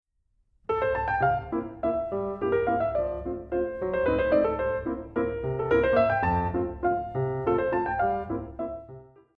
mp3Barès, Basile, La Capricieuse for piano, Valse, Op. 7, mm. 20-27